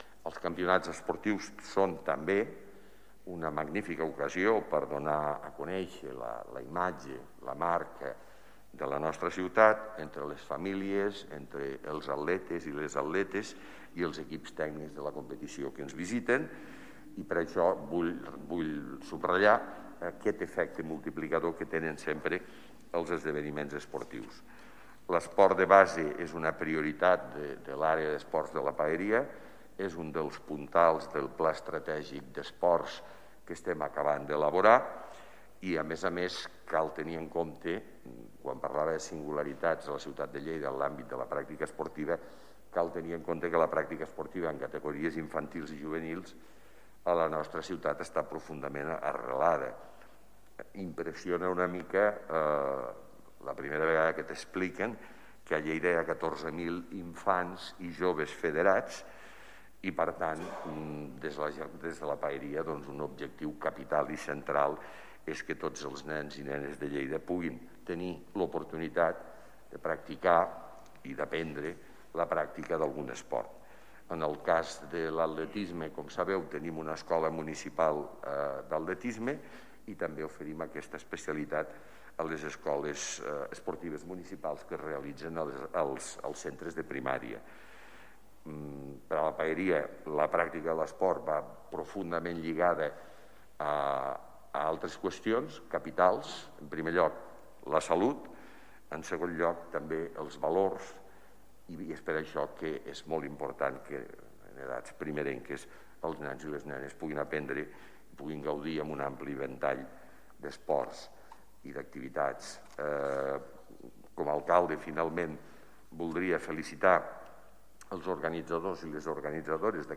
Tall de veu M.Pueyo